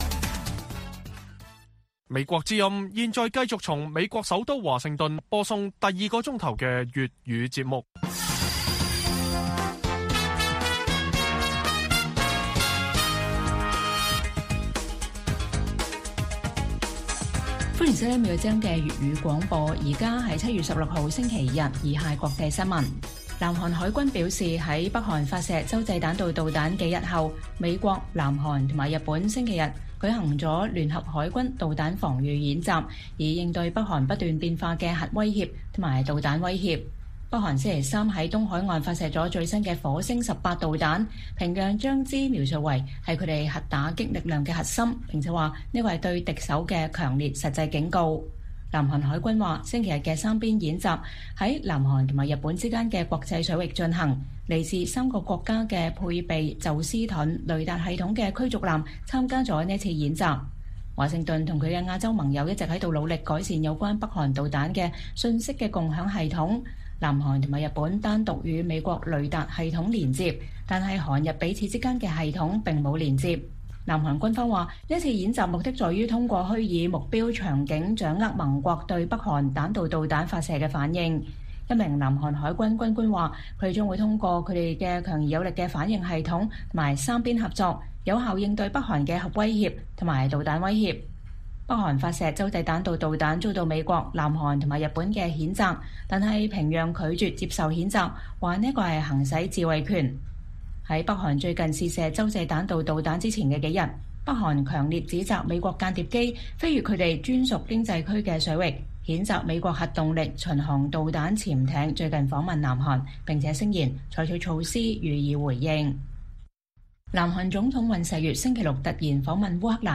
粵語新聞 晚上10-11點: 美韓日舉行聯合海軍導彈防禦演習